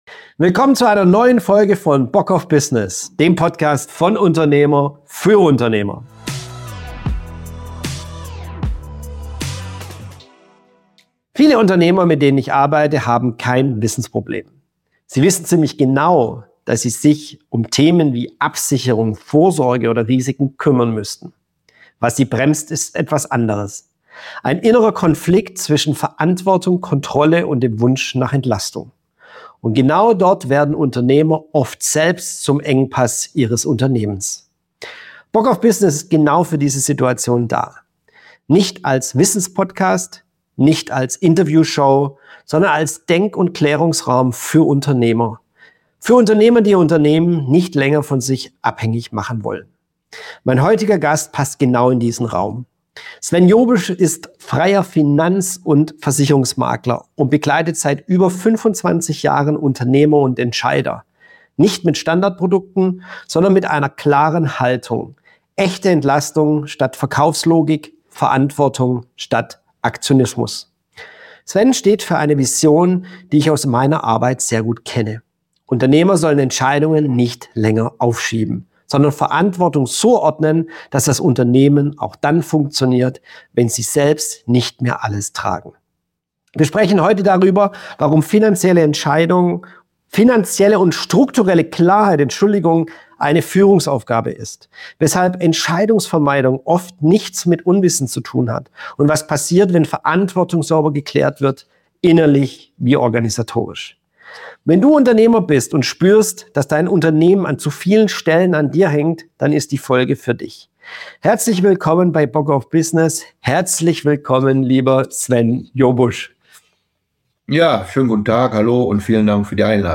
Ein Gespräch über Haltung statt Aktionismus, über Klarheit als Führungsaufgabe – und darüber, was passiert, wenn Verantwortung nicht weitergeschoben, sondern sauber geklärt wird.